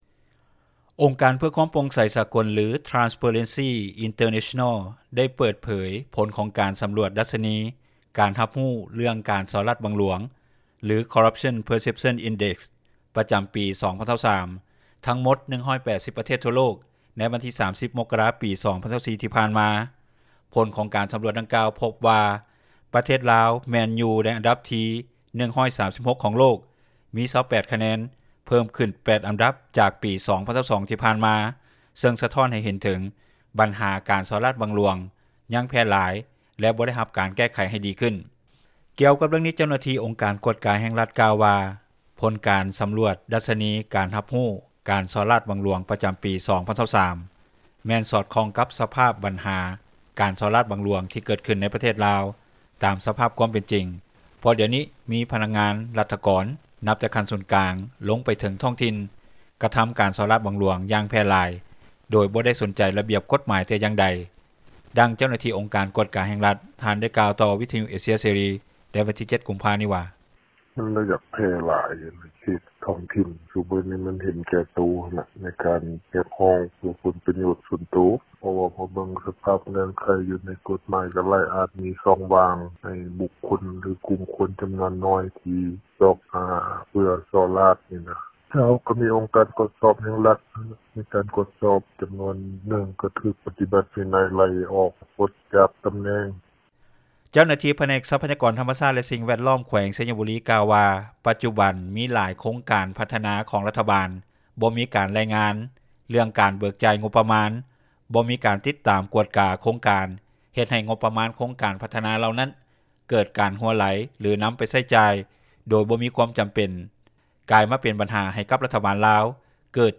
ດັ່ງຊາວແຂວງສະຫວັນນະເຂດ ທ່ານນຶ່ງ ກາວຕໍ່ວິທຍຸເອເຊັຽເສຣີ ໃນມື້ດຽວກັນນີ້ວ່າ:
ດັ່ງຊາວແຂວງຈຳປາສັກ ທ່ານນຶ່ງກ່າວຕໍ່ວິທຍຸເອເຊັຽເສຣີ ໃນມື້ດຽວກັນວ່າ:
ດັ່ງທ່ານ ຄຳປະສິດ ເທບວົງສາ ສະມາຊິກສະພາແຫ່ງຊາຕ ແຂວງເຊກອງໄດ້ກ່າວ ໃນກອງປະຊຸມສໄມ ສາມັນເທື່ອທີ 6 ຂອງສະພາແຫ່ງຊາຕ ຊຸດທີ 9 ໃນວັນທີ 07 ພຶສຈິກາ 2023 ຜ່ານມາວ່າ: